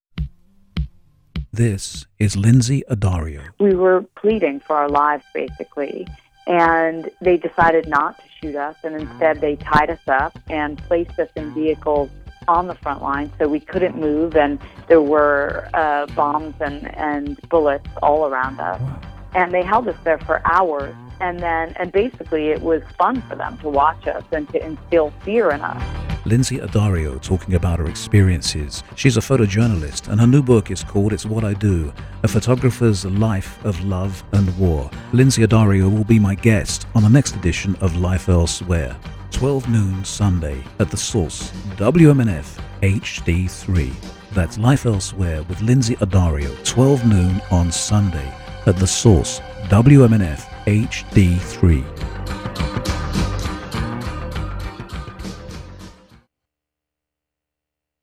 Below is a brief example of a riveting interview we urge you not to miss.